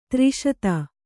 ♪ tri śata